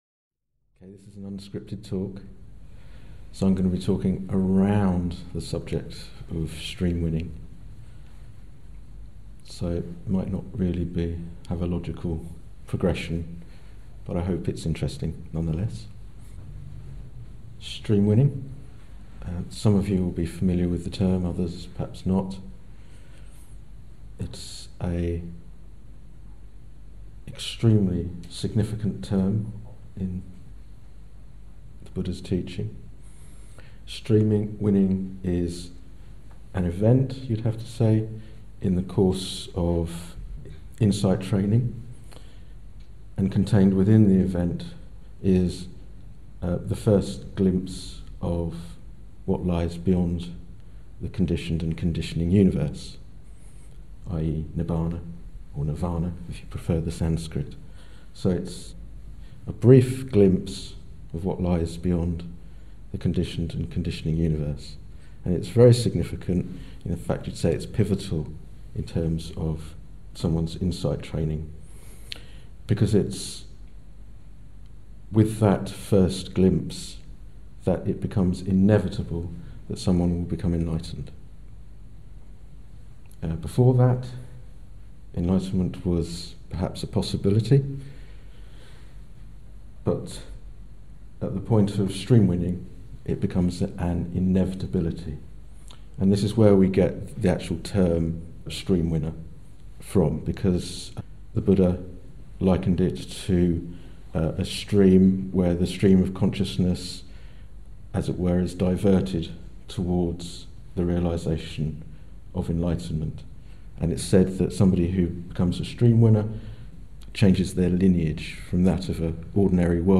This talk looks at the vipassana meditator's journey to stream-winning, the first of the four paths that make up the Buddhist insight-wisdom training. The relationship between the fetters of personality belief, sceptical doubt and rule and ritual, and how they interfere with progress on the first path are described.